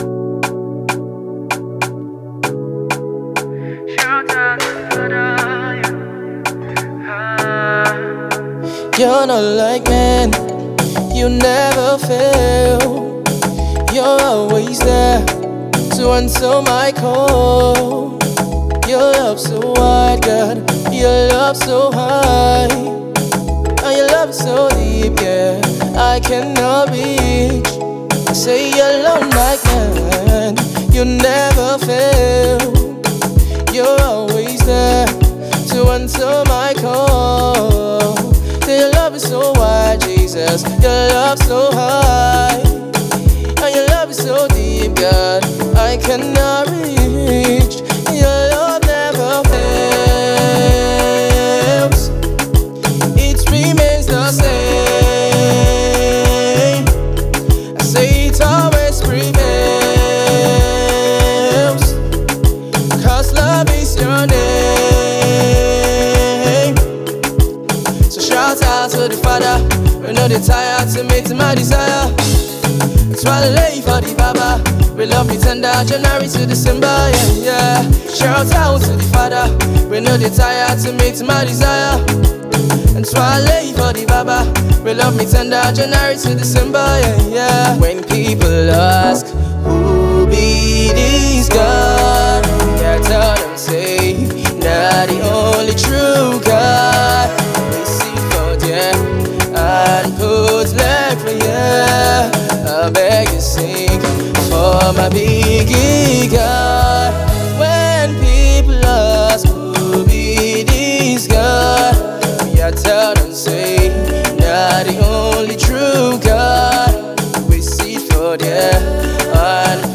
mid tempo afro tune